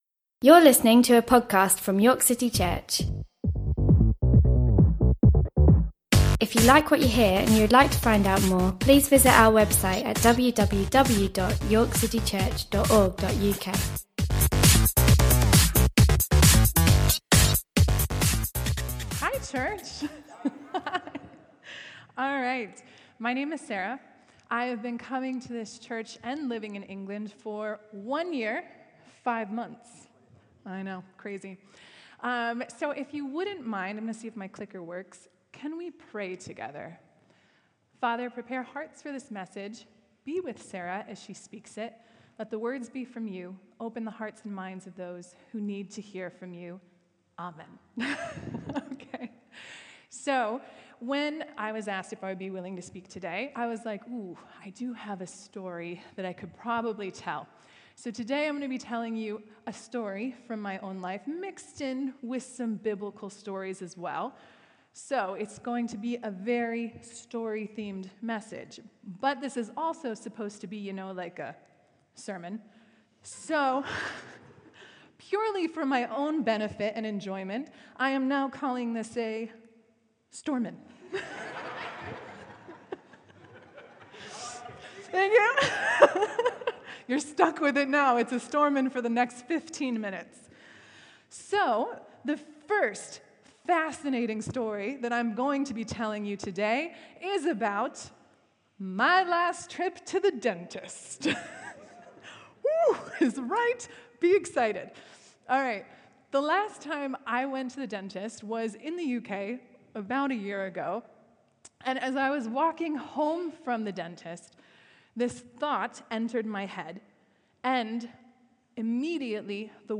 A one-off message, often by a visiting speaker.